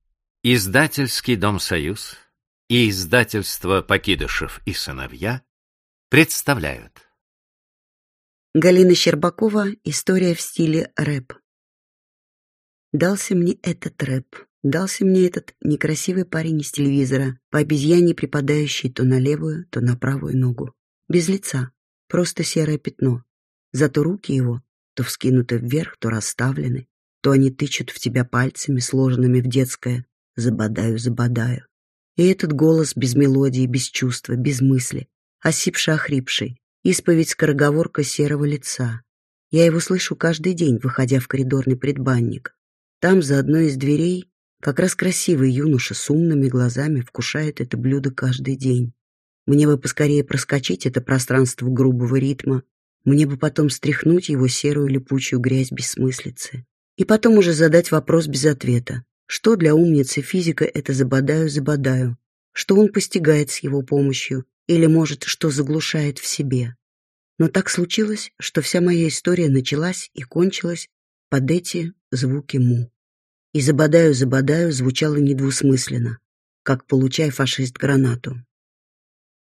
Аудиокнига История в стиле рэп | Библиотека аудиокниг
Aудиокнига История в стиле рэп Автор Галина Щербакова Читает аудиокнигу Евгения Добровольская.